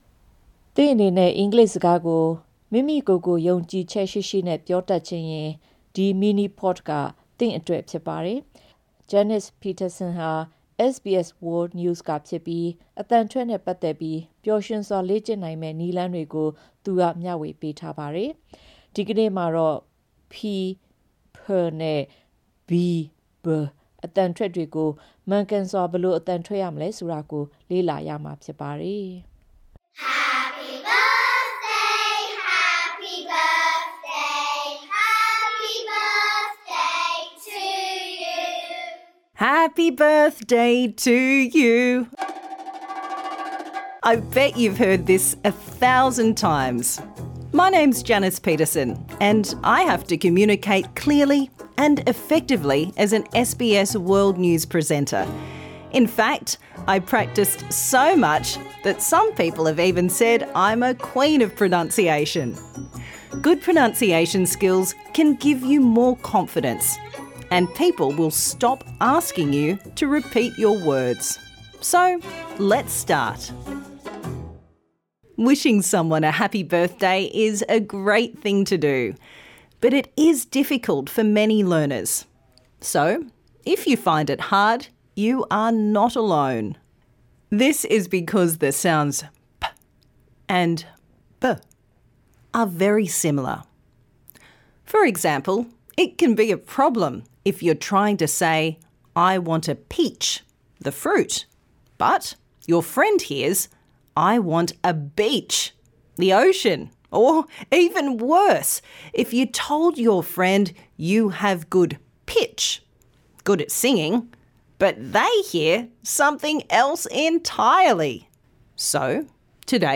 This lesson suits all learners at all levels.